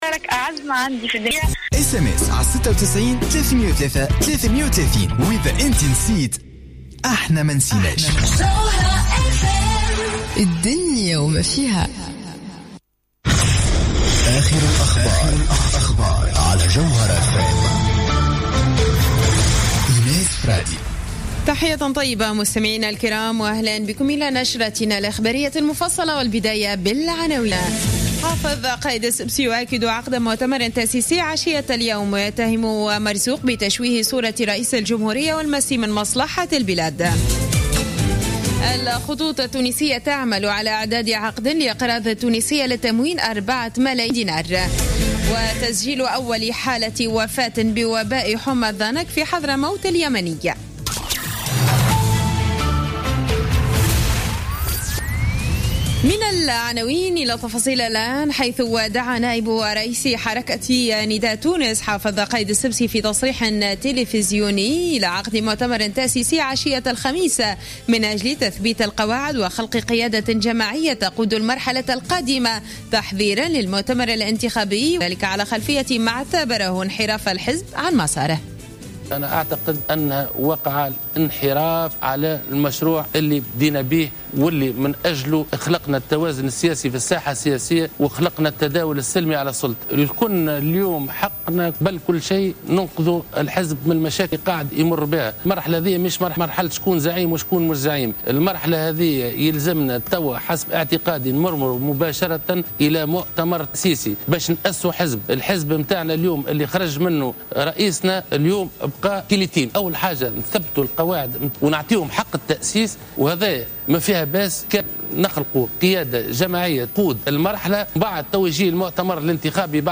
نشرة أخبار منتصف الليل ليوم الخميس 11 نوفمبر 2015